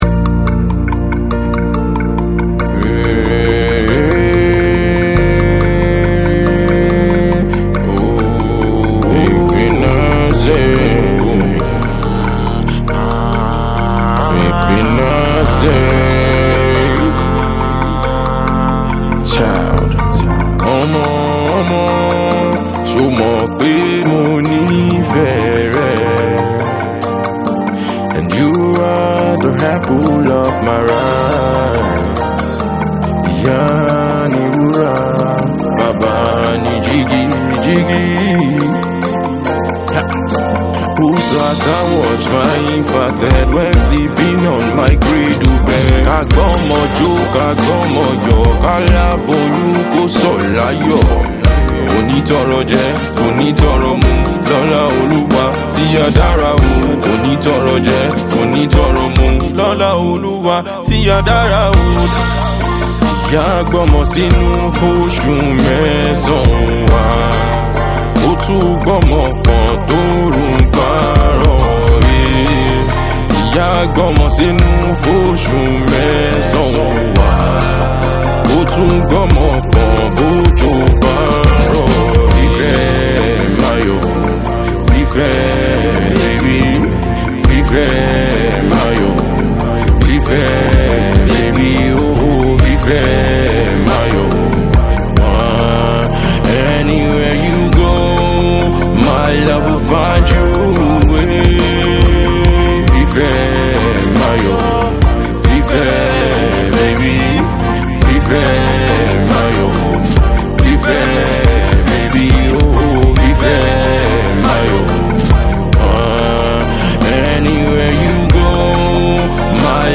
definitely an introspective number